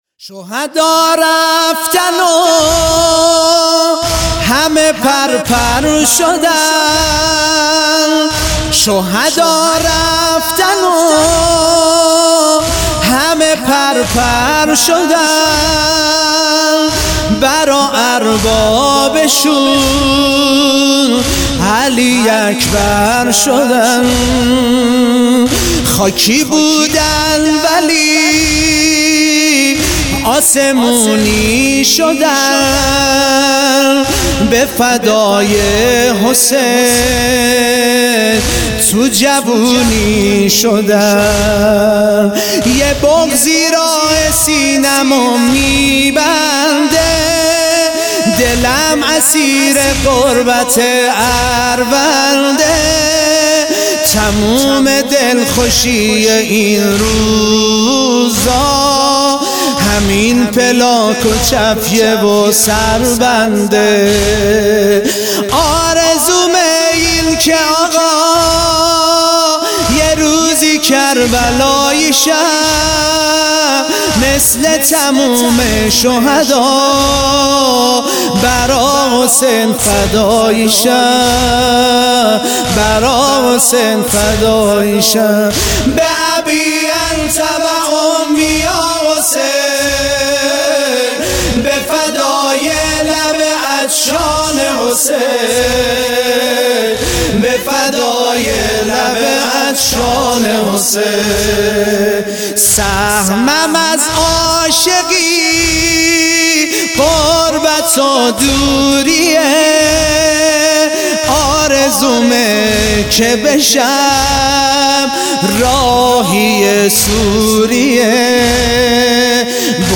محرم 99
نوحه شیرازی